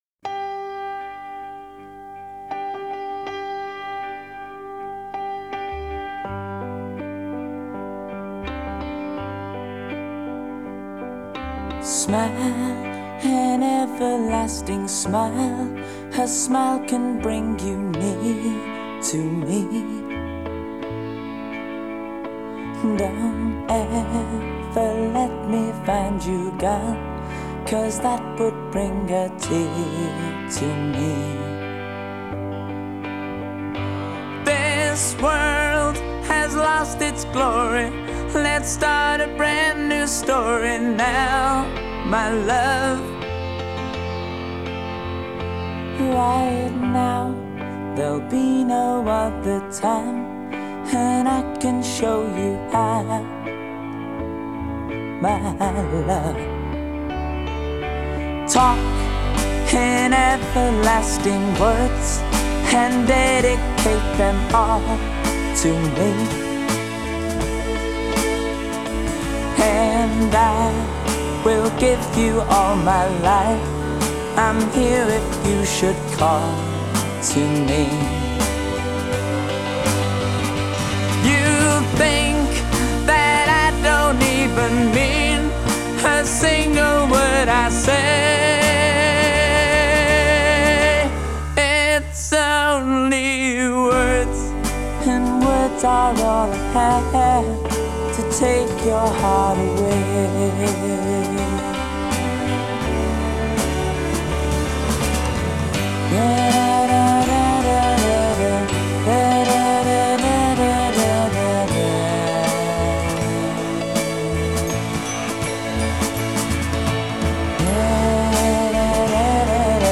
Genre: Pop, Pop Rock, Disco